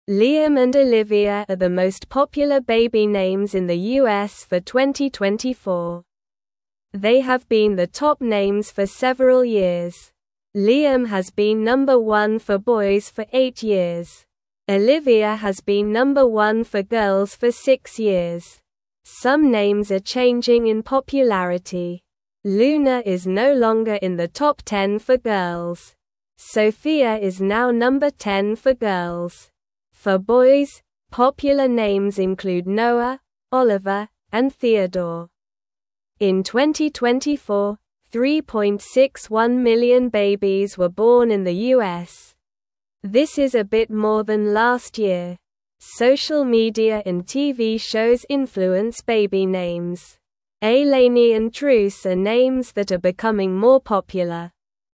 Slow
English-Newsroom-Lower-Intermediate-SLOW-Reading-Liam-and-Olivia-Are-Top-Baby-Names-for-2024.mp3